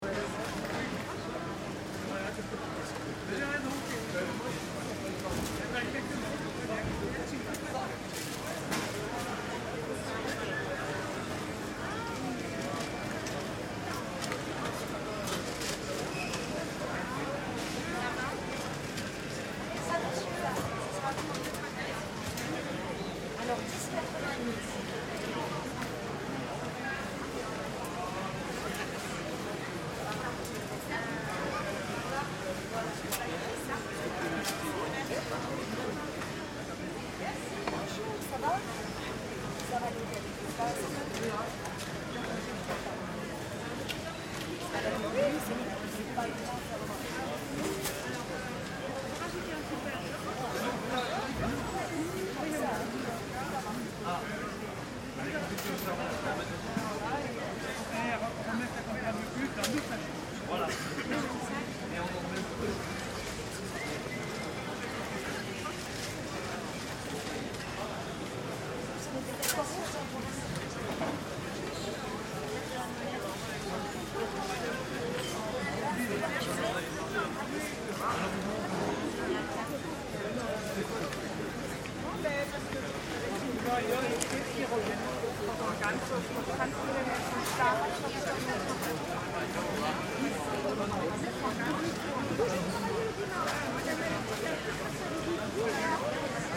Ambience, Market, Morning, Market, Crowd, Vendors, Exterior, Public Square, Pedestrians, Small...mp3